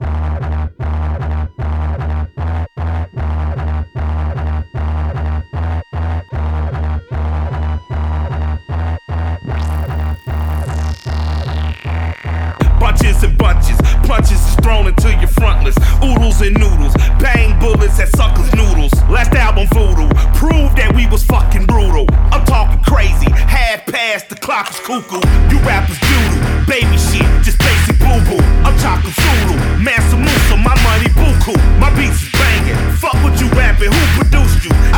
Жанр: Хип-Хоп / Рэп / Альтернатива
Hardcore Rap, Underground Rap, Dirty South, Alternative Rap